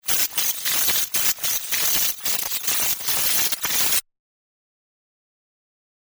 And that’s the way it is, too, only these mirror frequencies (aliasing) are so diverse that they take on a character of their own.
That is the minuet from Mozart’s Jupiter Symphony, speeded up 128-fold through packing.
menuett_pack128.mp3